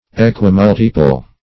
Equimultiple \E`qui*mul"ti*ple\, a. [Equi- + multiple: cf. F.